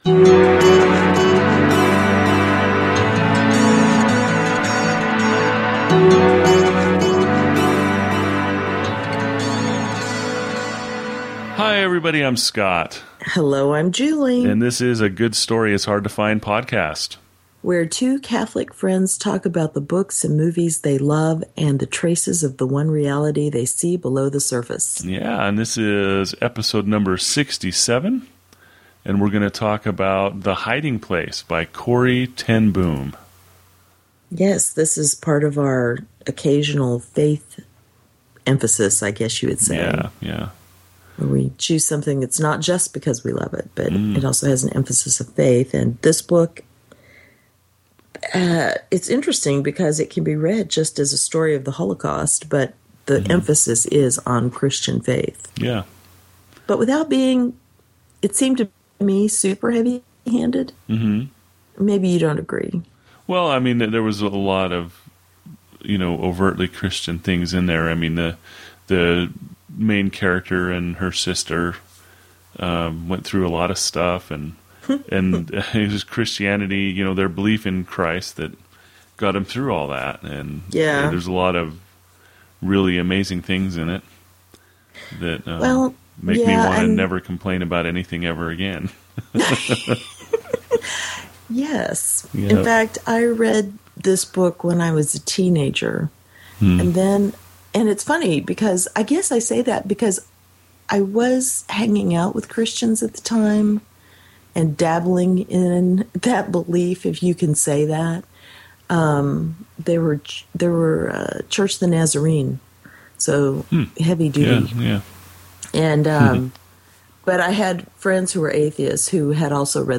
Two Catholics talking about books, movies and traces of "the One Reality" they find below the surface.